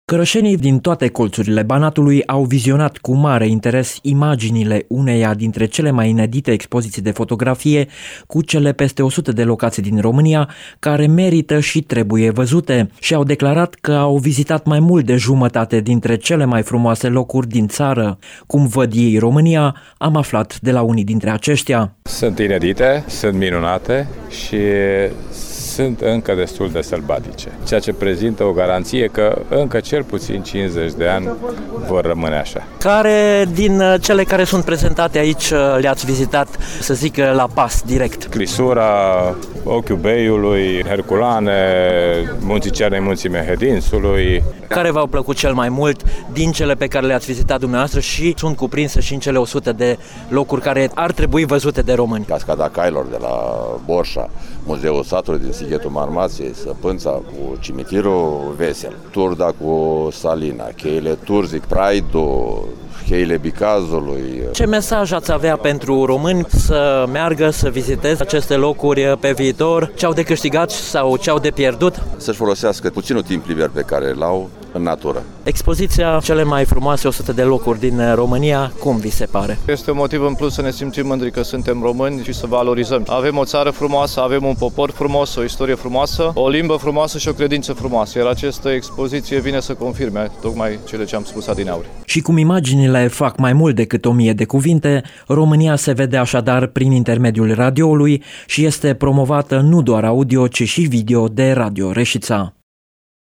Cetățenii, veniţi cu mic cu mare, din toate colţurile județului Caraș-Severin au admirat imaginile, şi au declarat reporterilor Radio România Reşiţa că au vizitat cele mai multe dintre cele mai frumoase locuri din ţară.